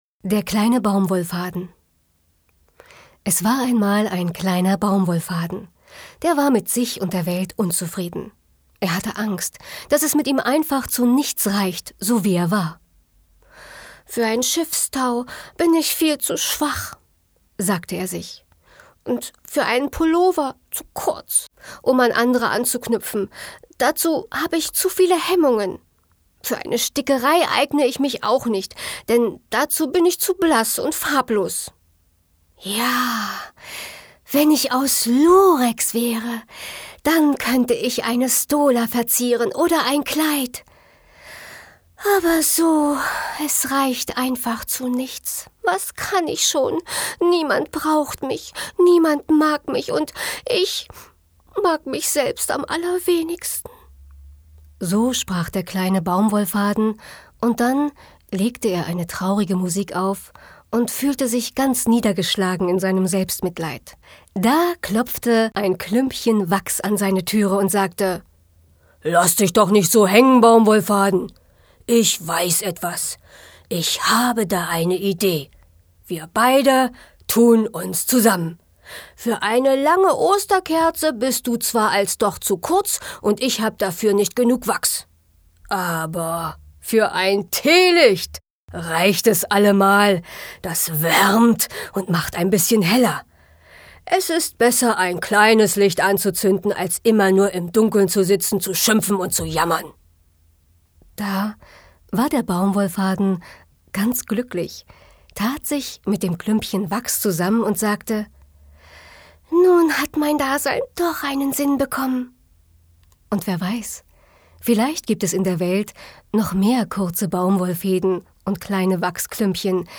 deutsche Sprecherin für Hörbuch, Hörspiel, Werbung, Dokumentarfilm, Voice over, Image- und Industriefilm, POS, Multimedia
Sprechprobe: Sonstiges (Muttersprache):
german female voice over artist.